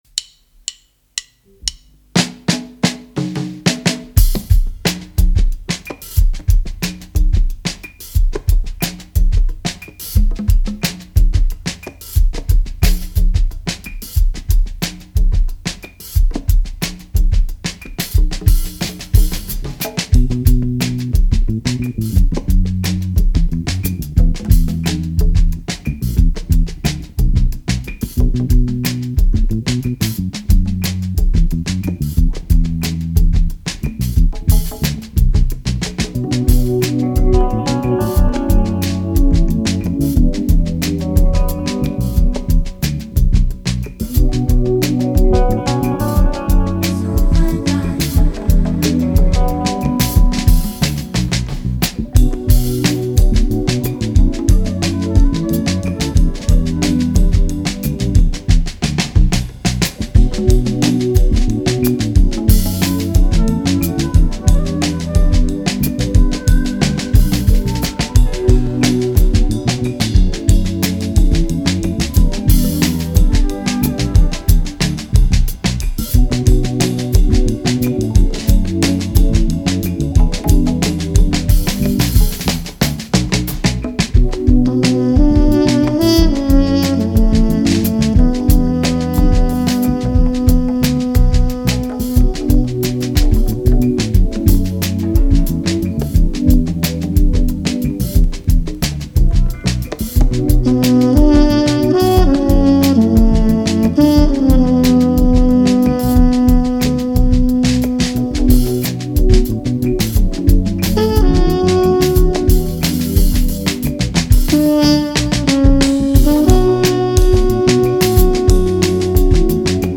Nigerian Afro-Highlife and Alternative Sound Music Duo